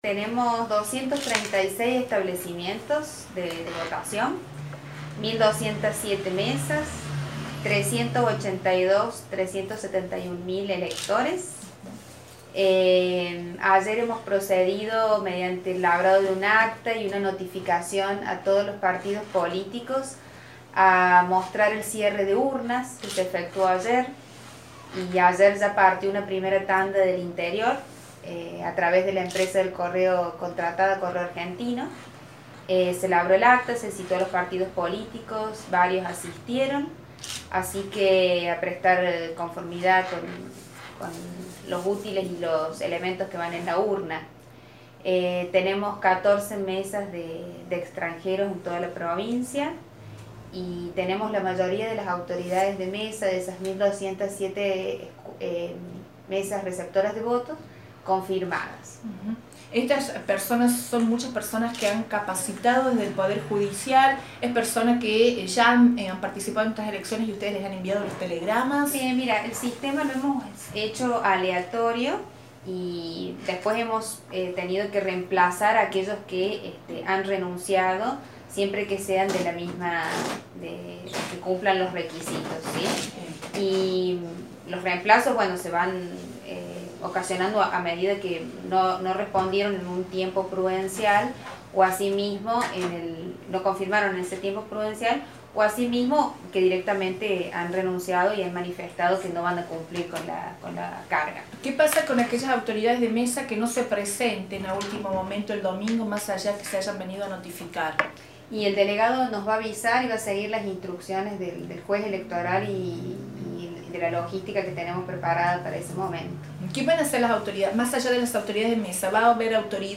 Asimismo, este jueves por la mañana la Secretaria Electoral Provincial, Dra. Ana Giménez Lanza, dio a conocer datos estadísticos e información básica para los electores.